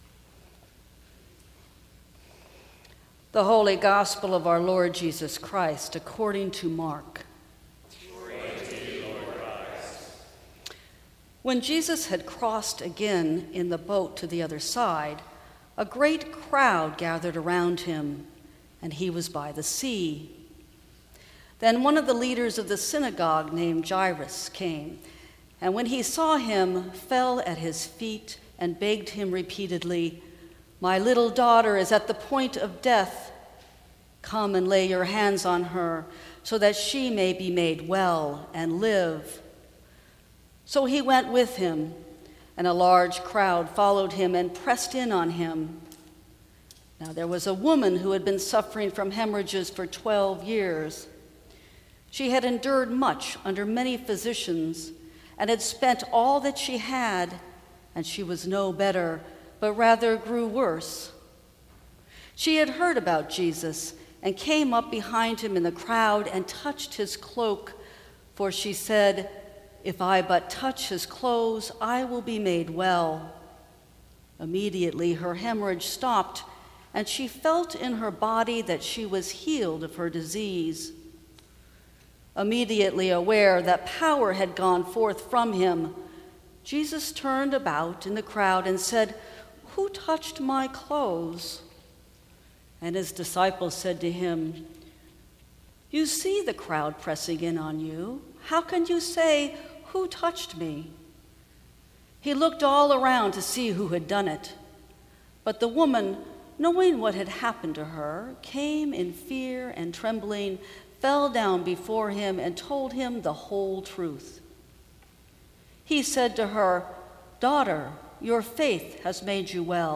Sixth Sunday After Pentecost
Sermons from St. Cross Episcopal Church Lift Every Voice and Sing Jul 05 2018 | 00:15:10 1x 00:00 / 00:15:10 Subscribe Share Apple Podcasts Spotify Overcast RSS Feed Share Link Embed